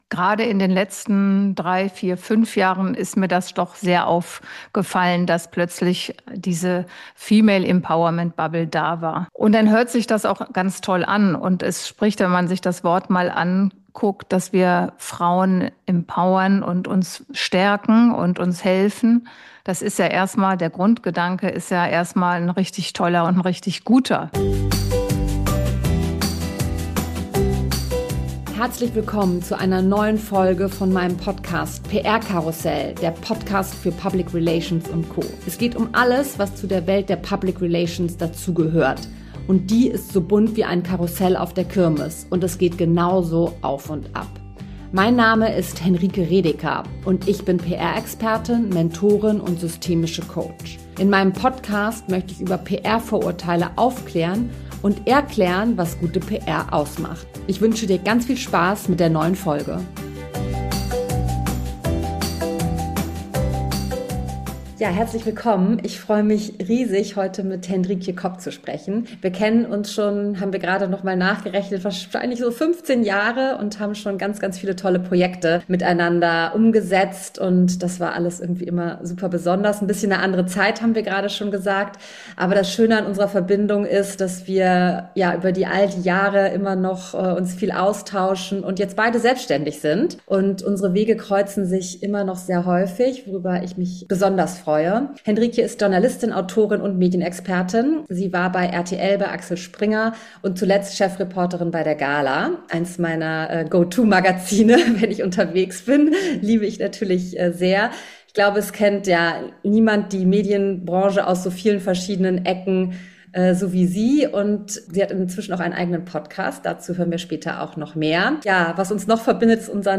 #53 Female Empowerment zwischen Buzzword und echter Haltung – im Gespräch
Ein ehrliches Gespräch über Schein und Sein im Showbusiness, über Haltung und über die Kraft, sich gegenseitig Türen zu öffnen.